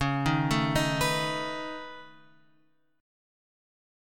C# 7th Suspended 2nd Sharp 5th